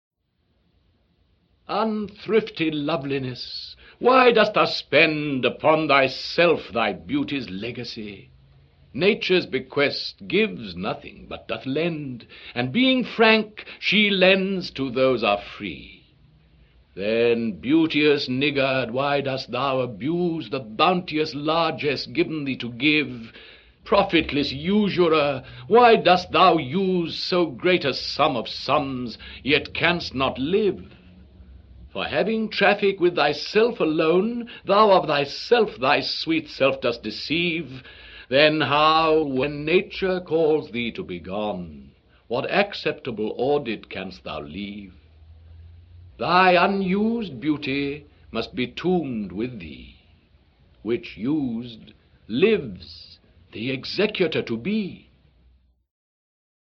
En voici une version lue par Sir John Gielguld :